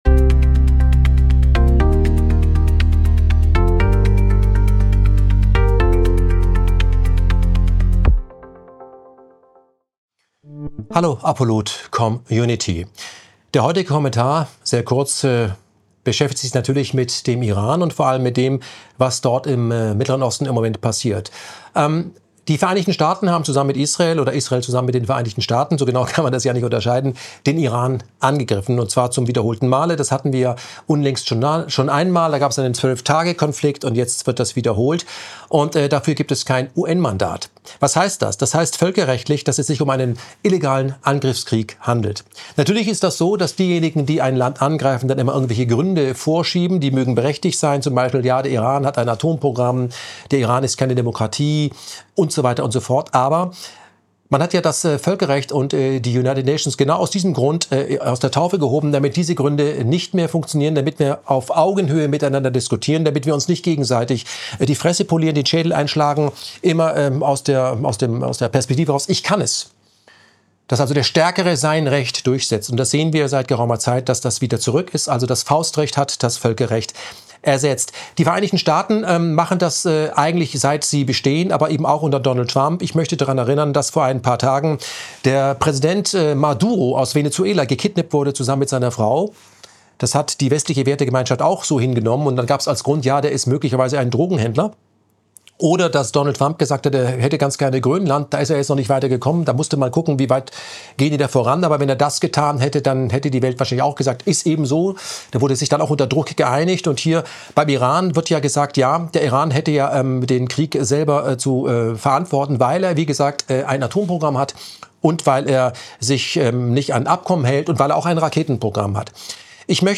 der-kommentar-12-faustrecht-apolut.mp3